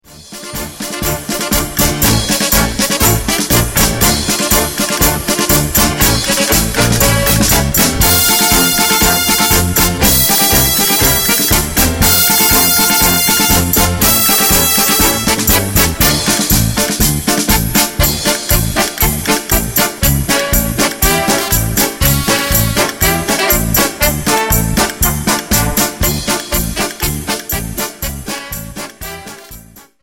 Dance: Paso Doble 60 Song